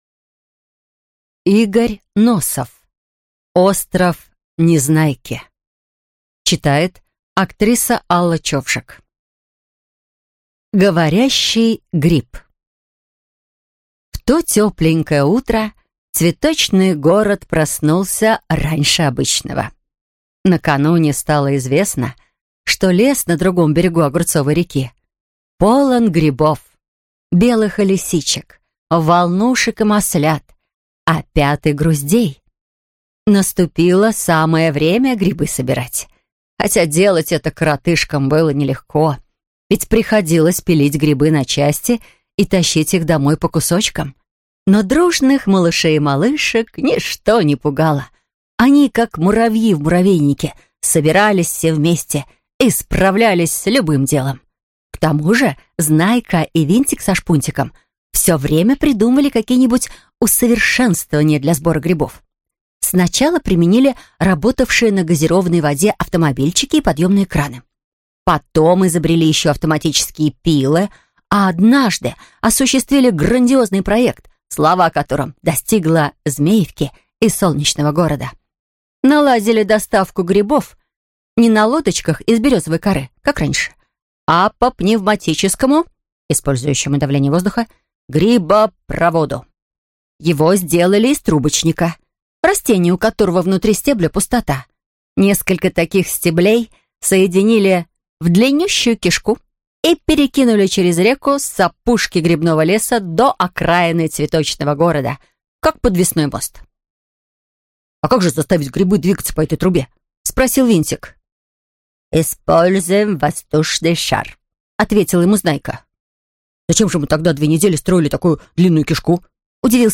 Аудиокнига Остров Незнайки. Рассказы | Библиотека аудиокниг